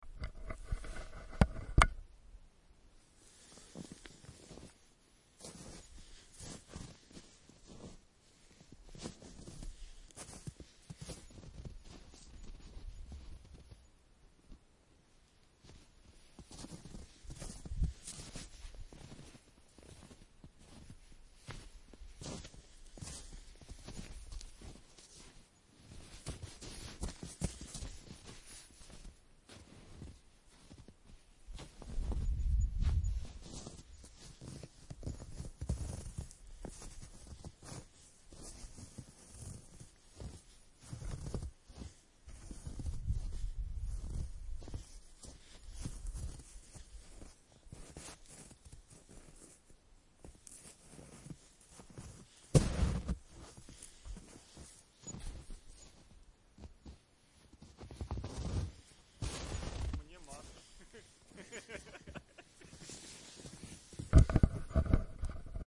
描述：在雪地上的脚步声。
Tag: 脚步 台阶 一步 走路 FIEL -recordings 领域 冬季 散步